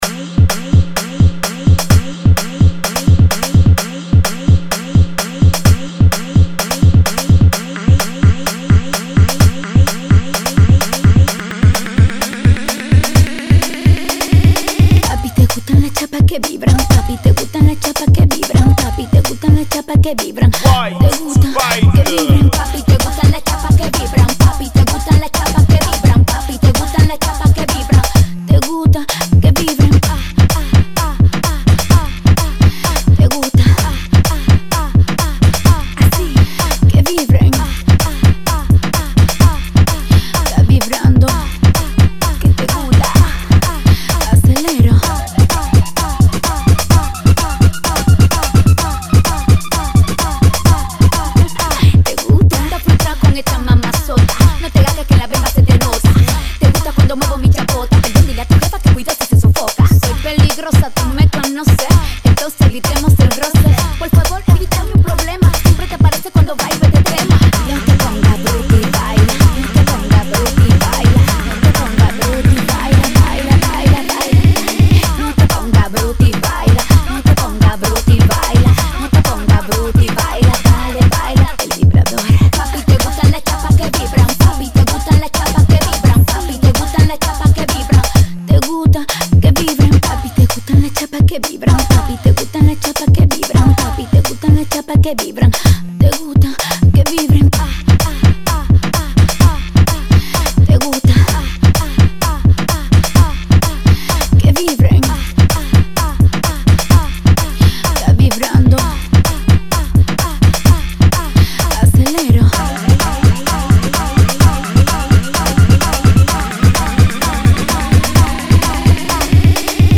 Funky [ 128 Bpm